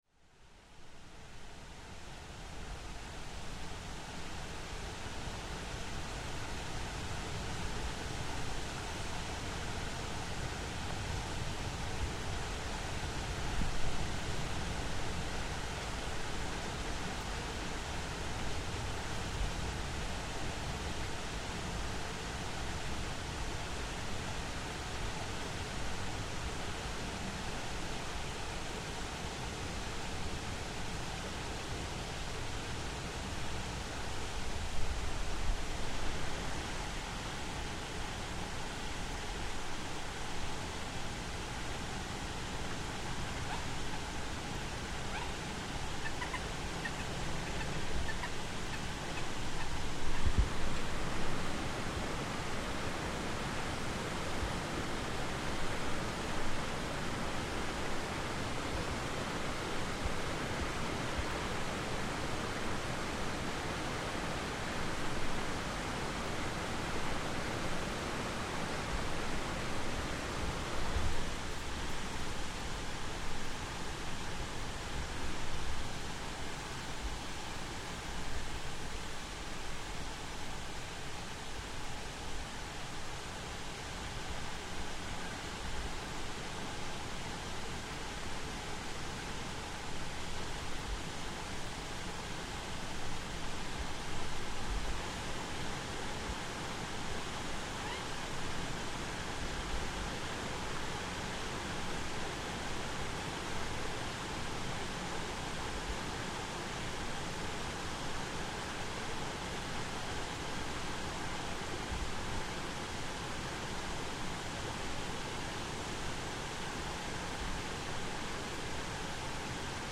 Flowing water next to a bridge, as a river passes through Treviso, Italy next to a railway bridge in a district known for its street art. Urban soundscape of passing trains meets nature.